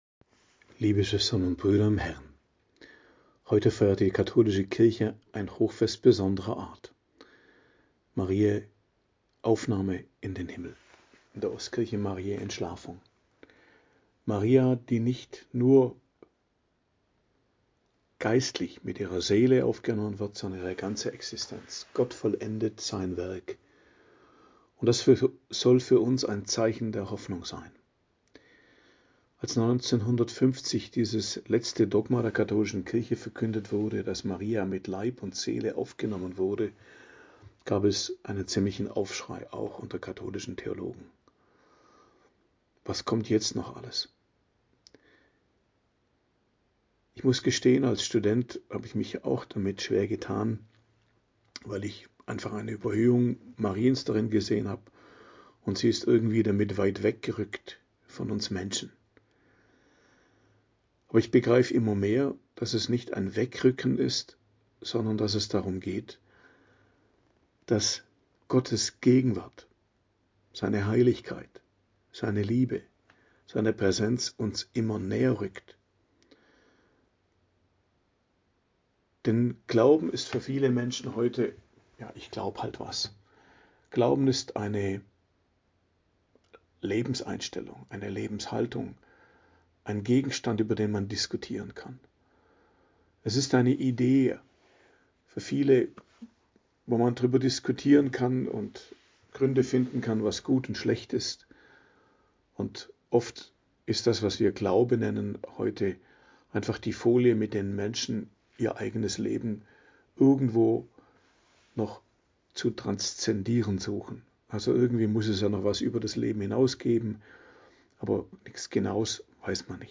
Predigt am Hochfest Mariä Aufnahme in den Himmel, 15.08.2025 ~ Geistliches Zentrum Kloster Heiligkreuztal Podcast